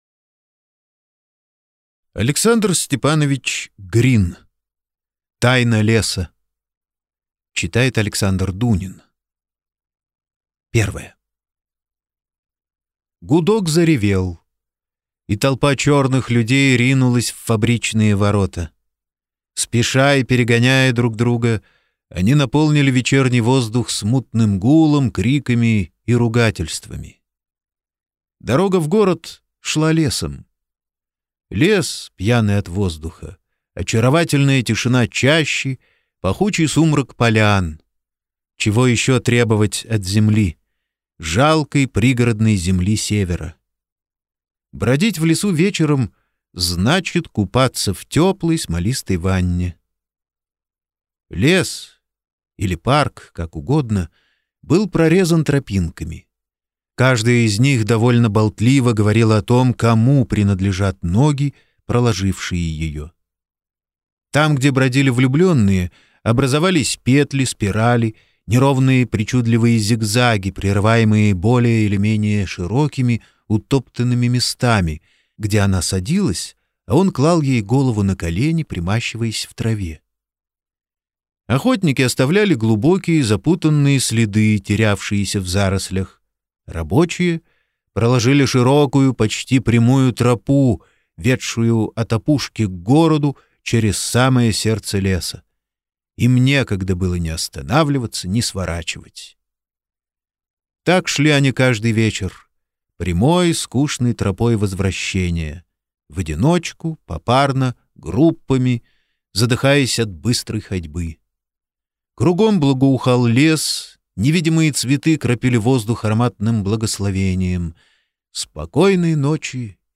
Аудиокнига Тайна леса | Библиотека аудиокниг